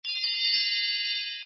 主角获取道具音效.mp3